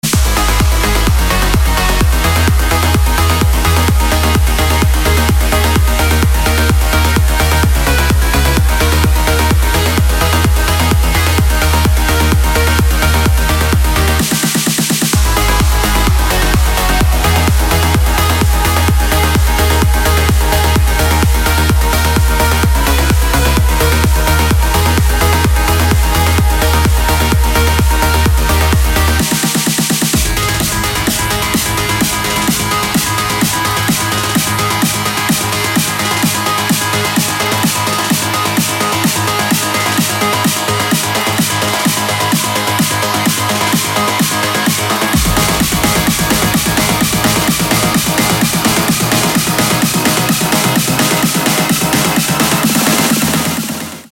dance
Electronic
без слов
progressive house
Trance
Стиль: Progressive House, Trance, Bigroom